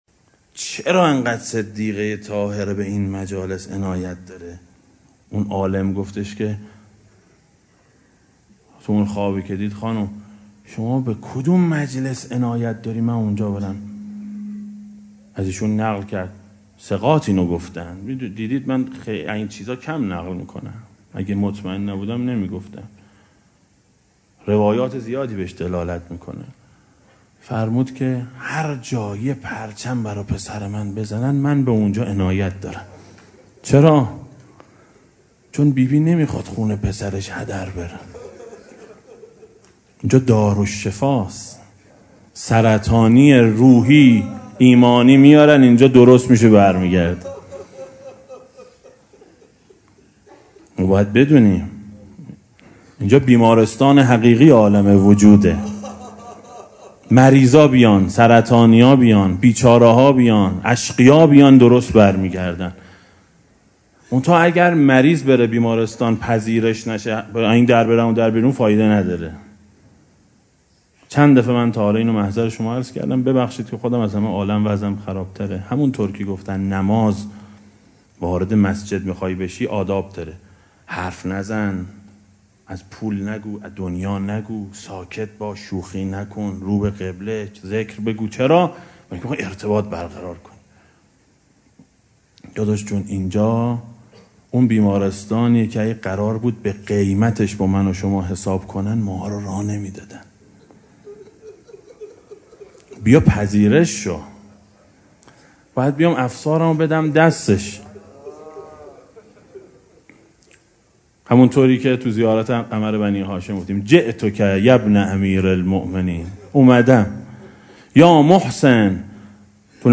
روضه شب عاشورای حسینی سال 1395 ـ مجلس دوم
دسته: امام حسین علیه السلام, روضه های اهل بیت علیهم السلام, سخنرانی ها